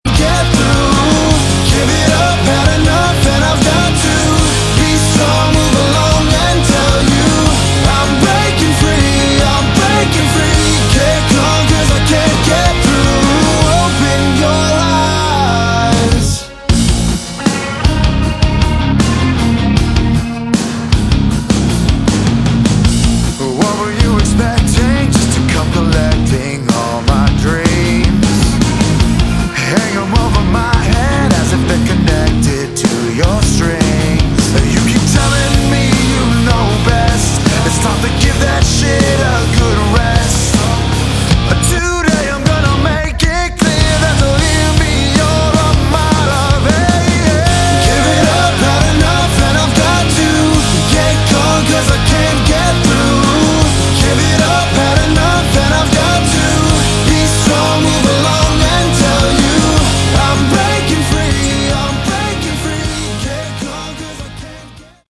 Category: Modern Hard Rock
lead guitar, vocals
drums
bass